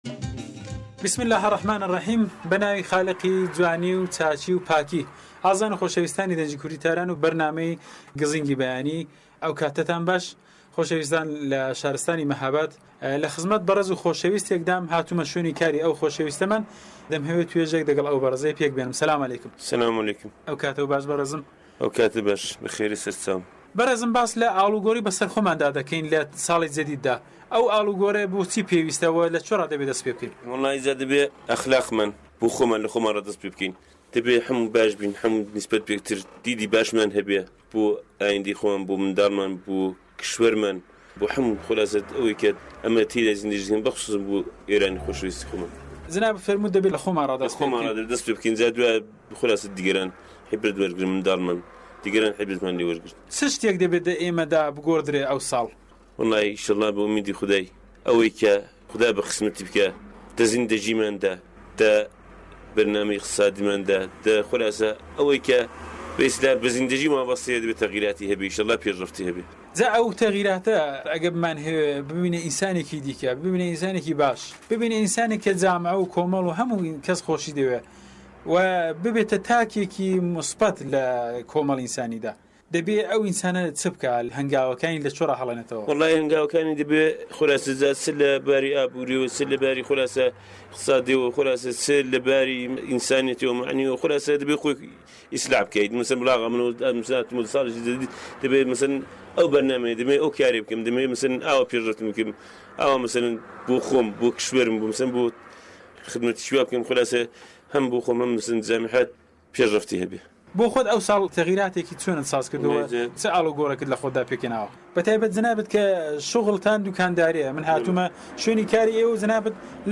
ڕاپۆرتێک له شاری مەهاباد سەبارەت به ئاڵ و گۆری به سەرخۆمان